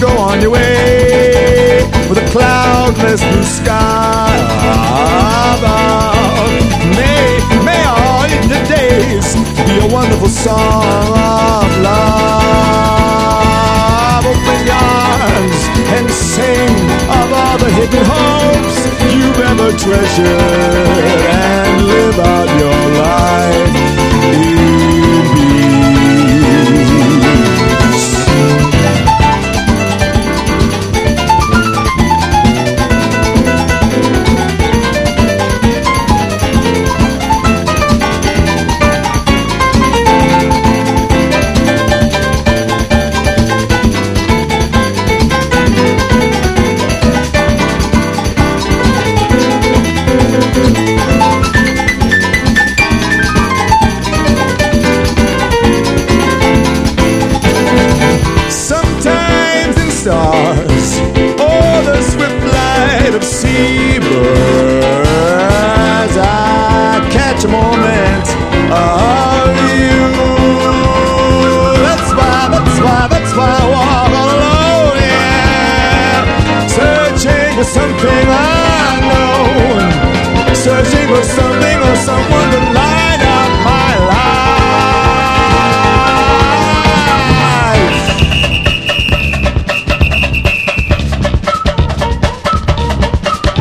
ROCK / 60'S / GARAGE PUNK / FRAT ROCK / SURF
トラッシュ・サーフ・ガレージ・クラシックをぎっしり収録！